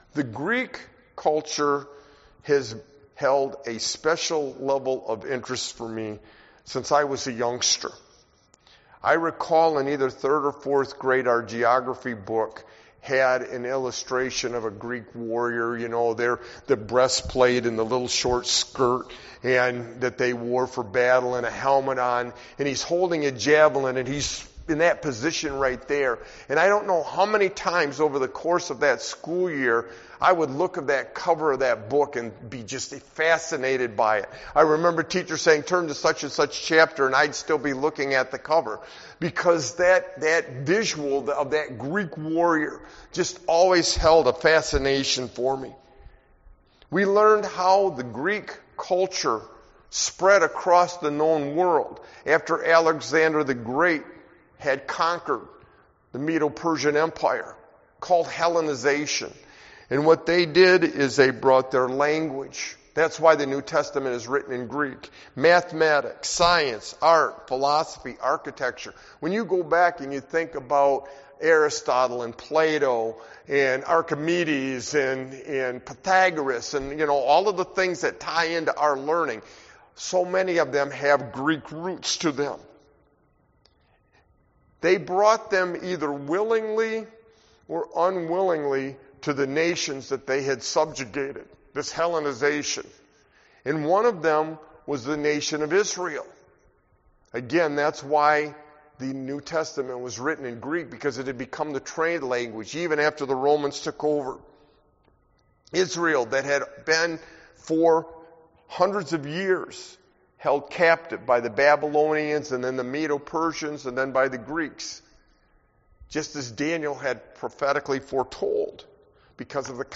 Sermon-Light-and-Darkness-LXV-61321.mp3